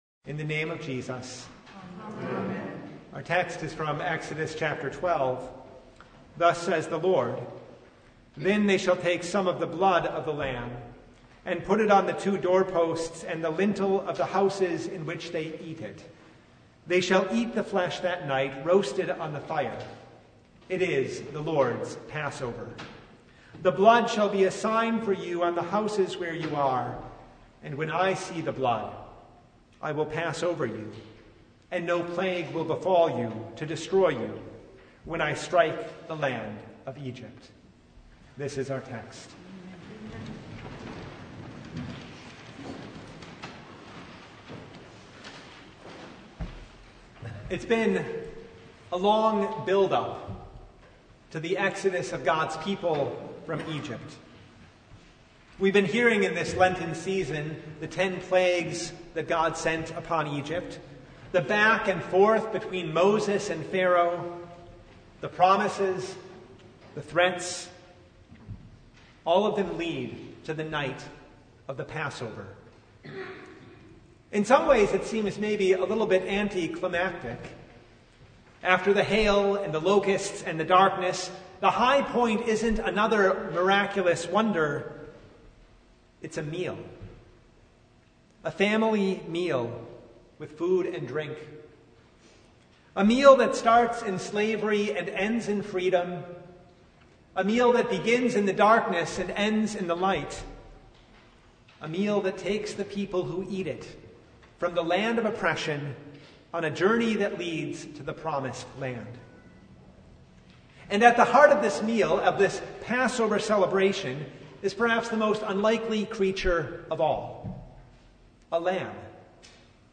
Exodus 12:1–14 Service Type: Maundy Thursday Holy Thursday takes us from the first Passover to the Passover of our Lord on the night when He was betrayed.